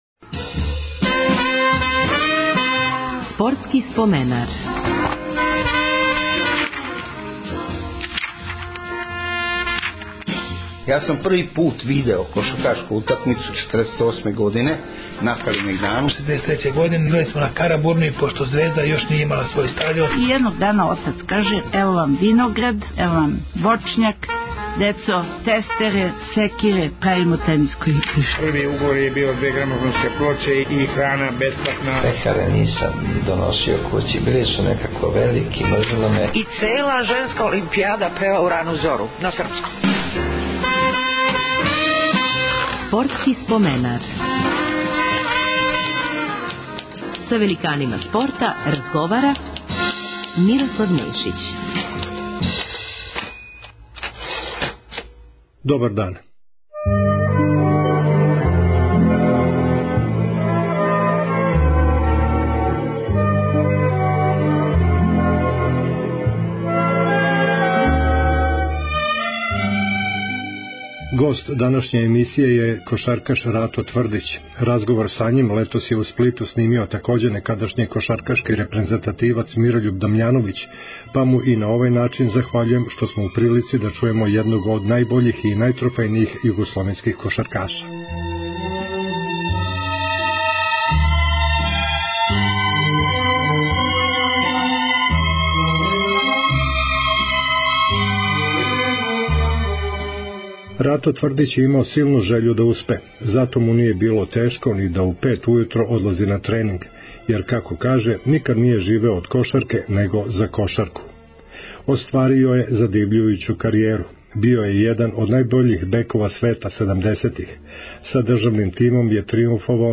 Гост 276. емисије је кошаркаш Рато Тврдић.